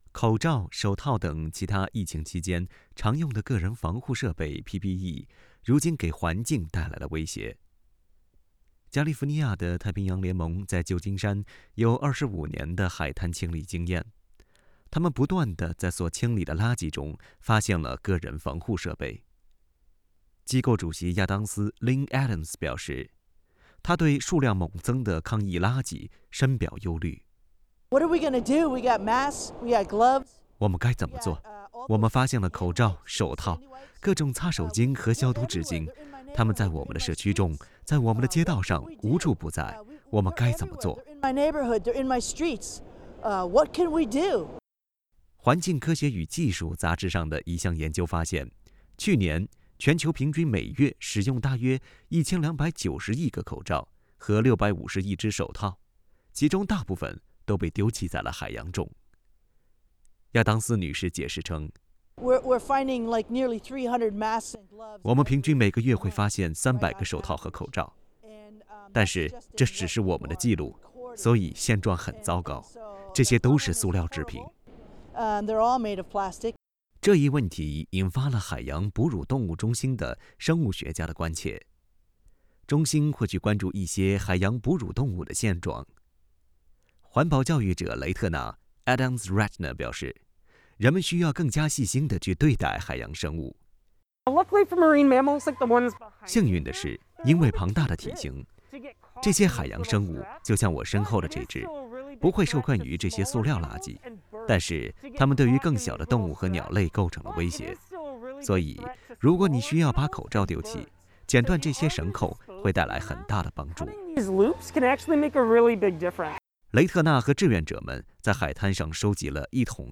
用于抗击新冠疫情的个人防护装备正在威胁海洋生物，它们会成为海滩上的塑料垃圾。（点击音频，收听采访）